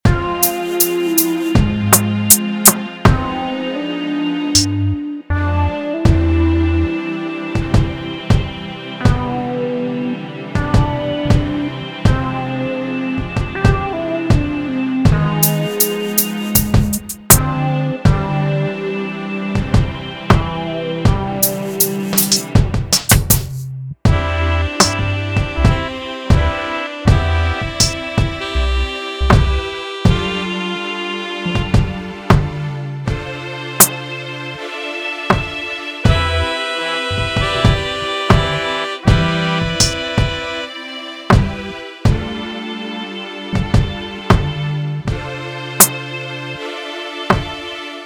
Hiphop, Rap beat